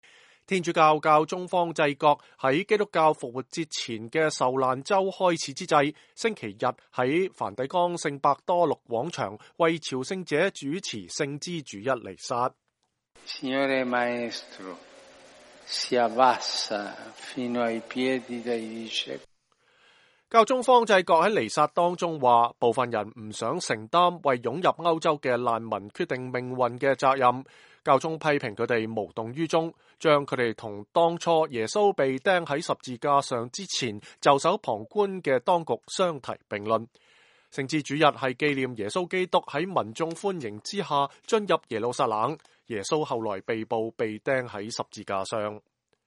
天主教教宗方濟各在基督教復活節前的受難週開始之際﹐星期日在梵蒂岡聖伯多祿廣場為朝聖者主持聖枝主日彌撒。教宗方濟各說，一些人不想承擔為湧入歐洲的難民決定命運的責任，批評他們“無動於衷”，把他們和當初耶穌被釘在十字架上之前袖手旁觀的當局相提並論。